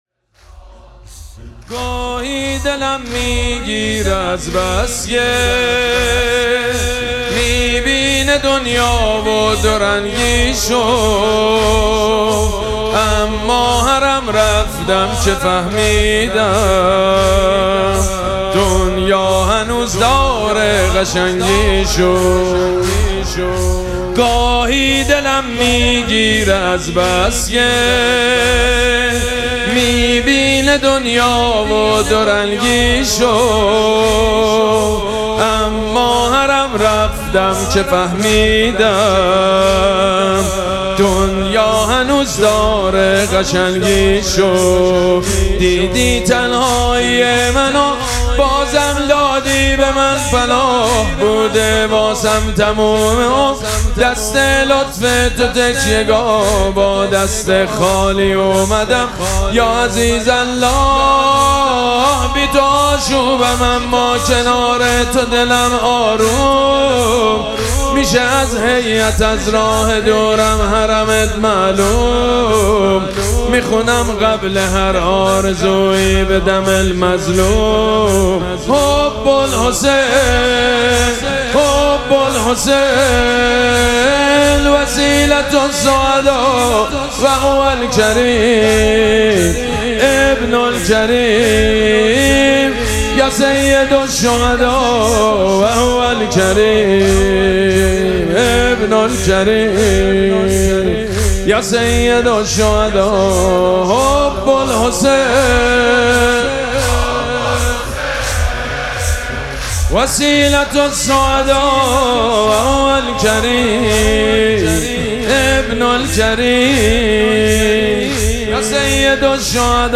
مراسم مناجات شب بیست و سوم ماه مبارک رمضان
مداح
حاج سید مجید بنی فاطمه